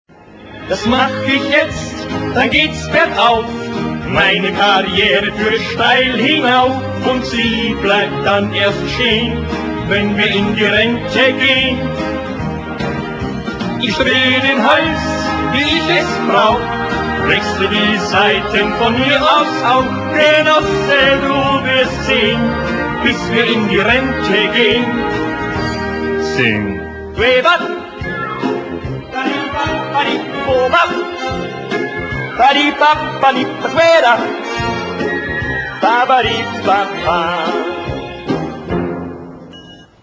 Lied